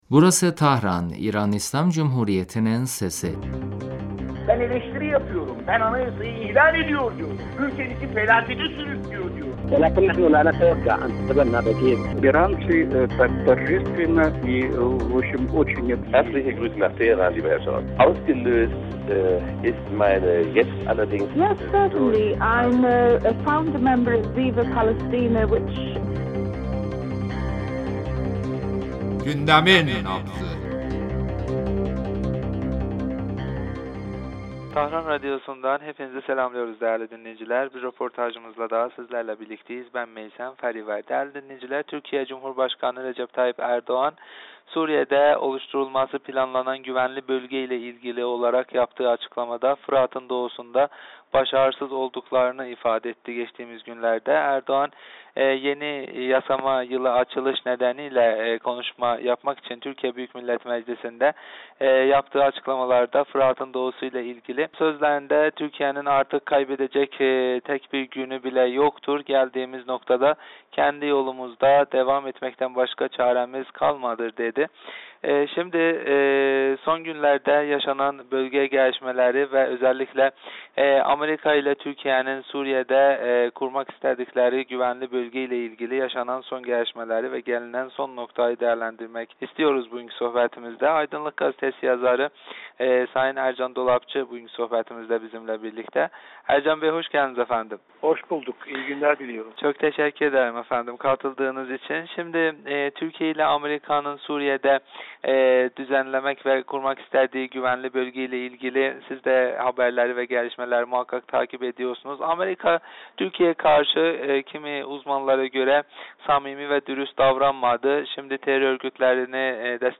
radyomuza verdiği demecinde Suriye krizi ile ilgili ABD'nin sürdürdüğü sinsi planları ve Suriye'de çözüme varma...